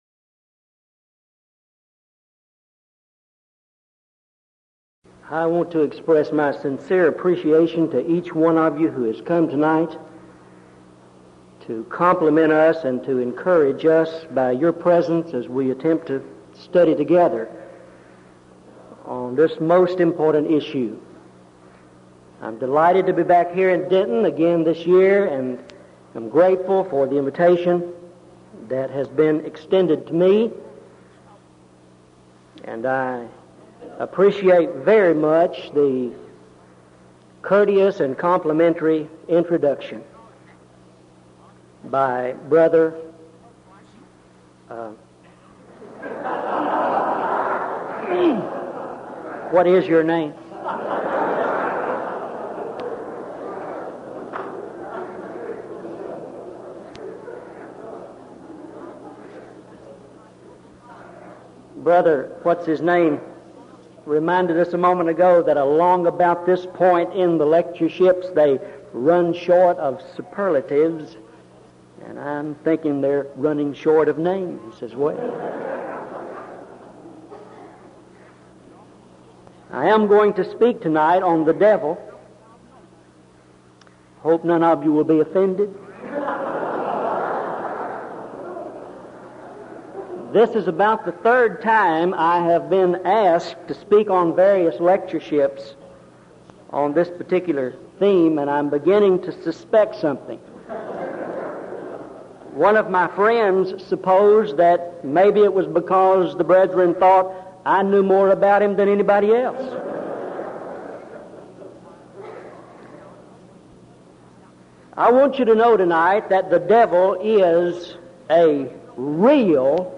Event: 1984 Denton Lectures
lecture